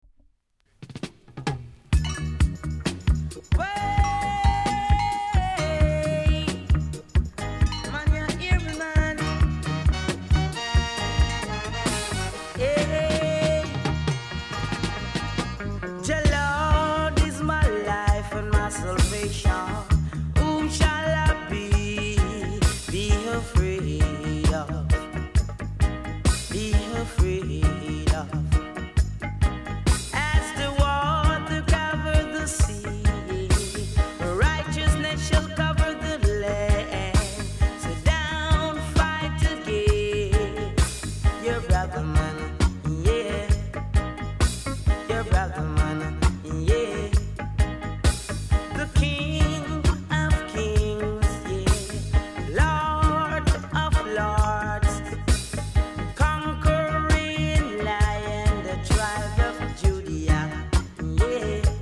※小さなチリノイズが少しあります。
コメント HEAVY ROOTS!!RARE!!※極僅かに反りがあります。